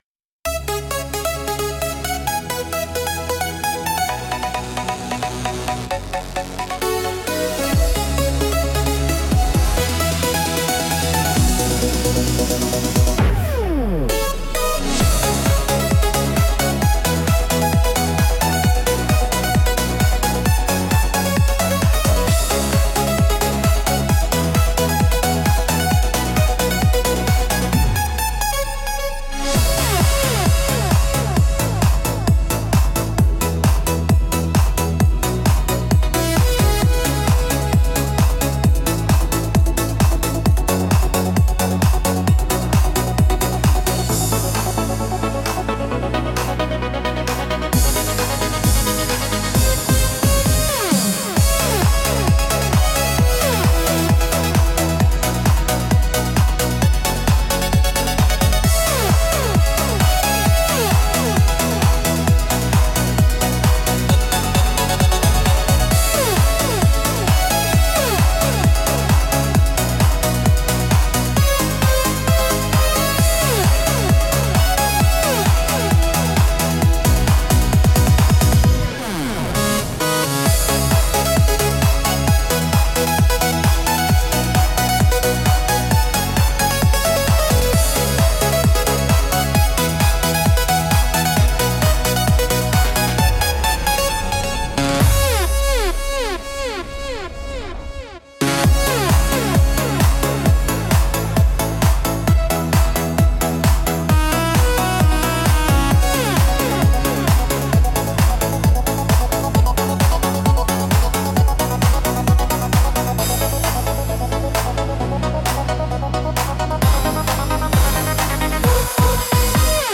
Instrumental - Velvet Rave 2.46